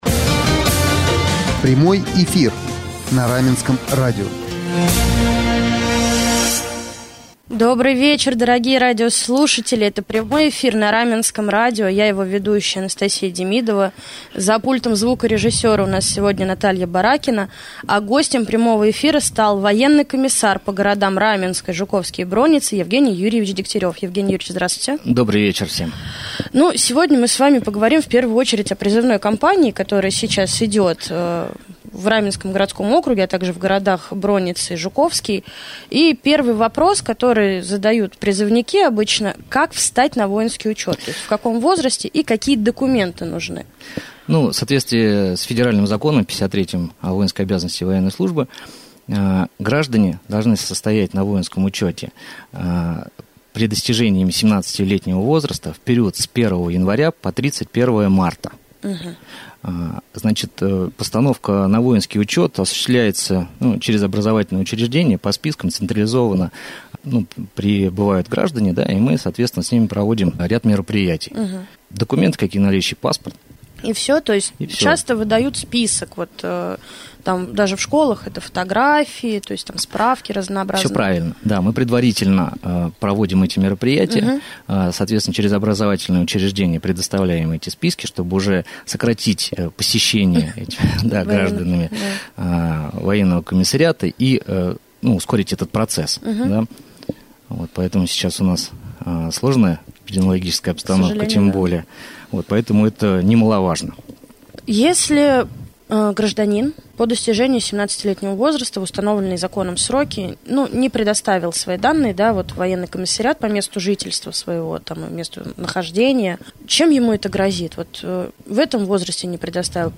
Военный комиссар по городам Раменское, Жуковский и Бронницы Евгений Юрьевич Дегтярев стал гостем прямого эфира на Раменском радио в среду, 2 декабря.